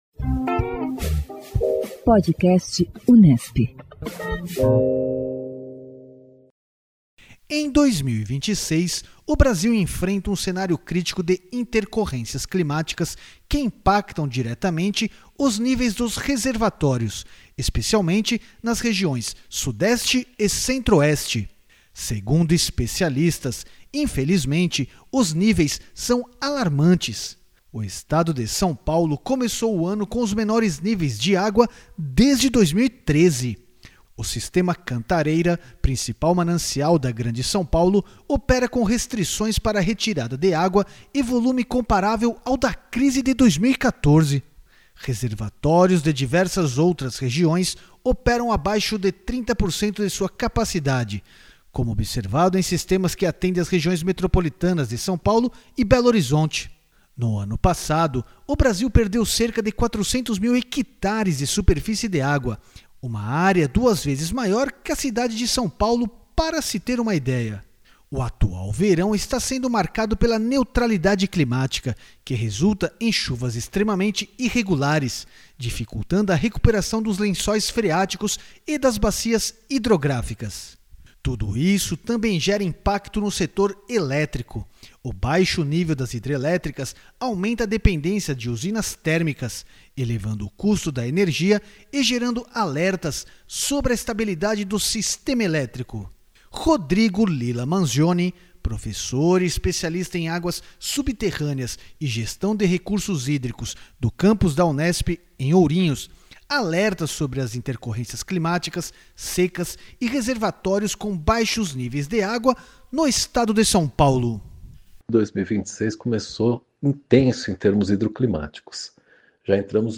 O PodAcqua traz trechos de entrevistas com especialistas da Unesp e de outras instituições, nas mais diferentes áreas do conhecimento, com atenção especialmente voltada à gestão responsável dos recursos hídricos.